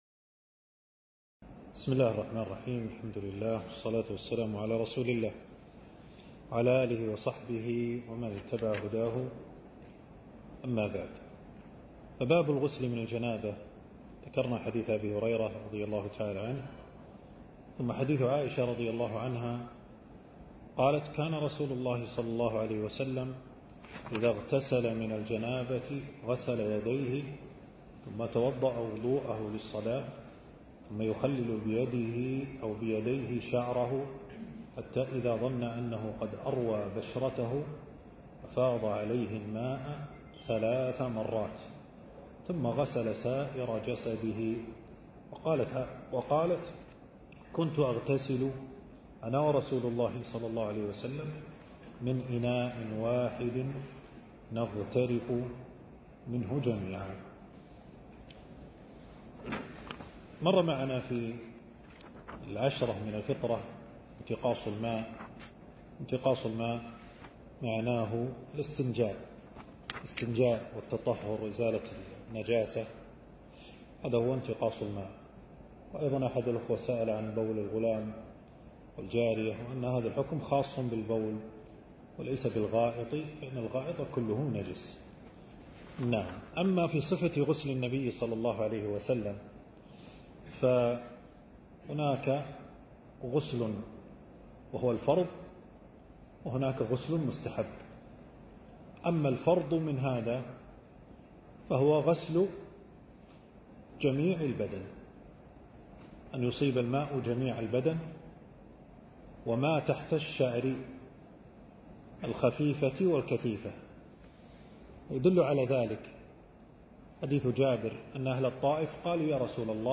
المكان: درس ألقاه في 2 جمادى الثاني 1447هـ في مبنى التدريب بوزارة الشؤون الإسلامية.